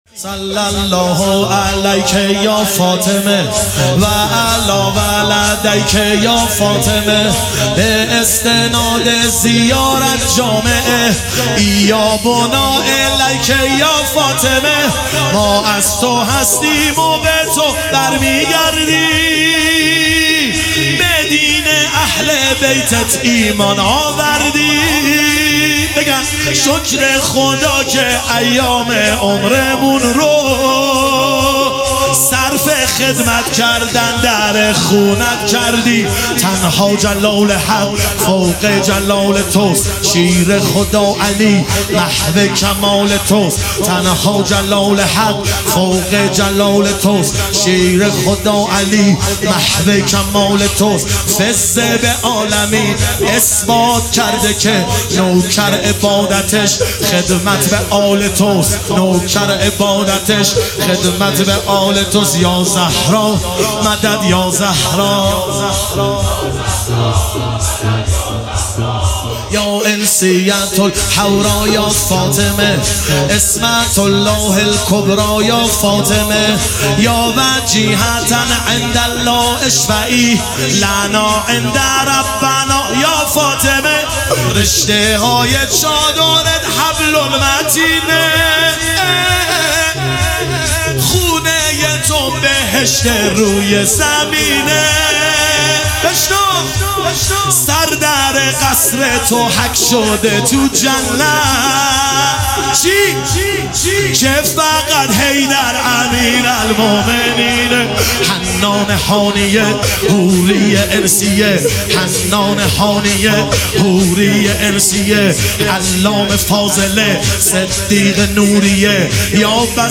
ایام فاطمیه 1399